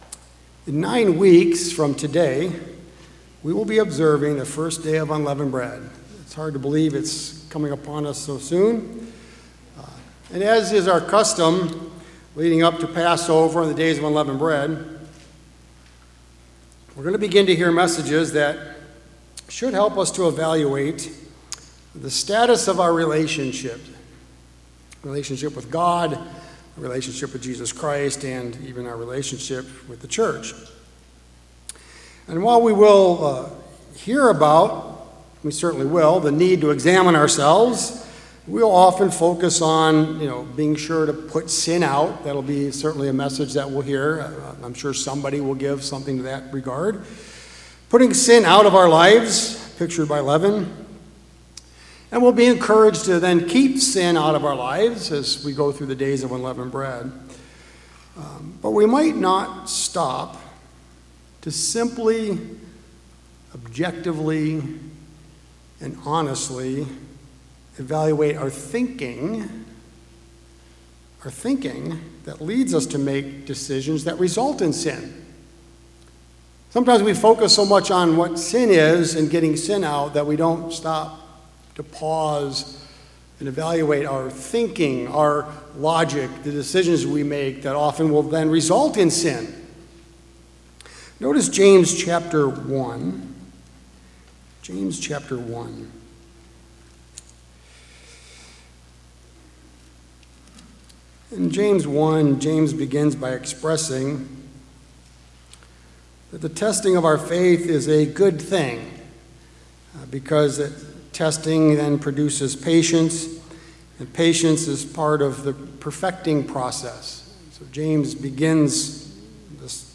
Given in Columbus, OH